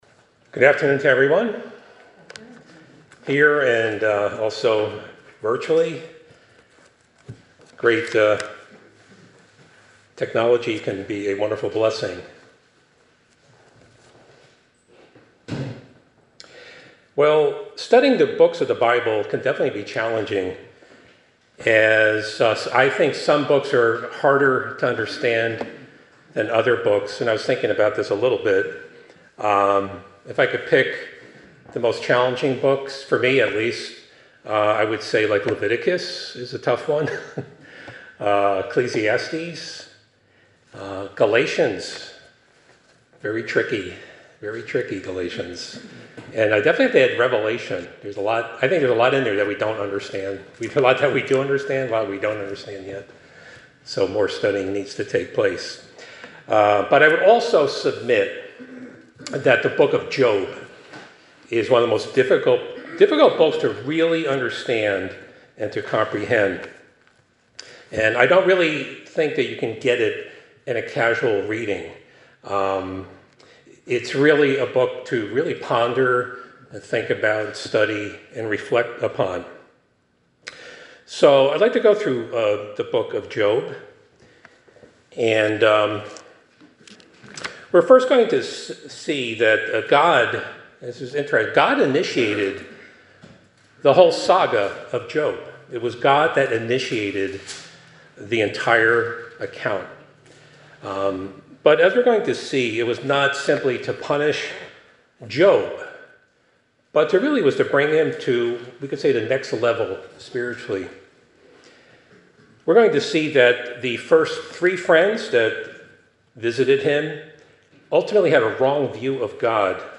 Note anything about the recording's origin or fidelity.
Given in Hartford, CT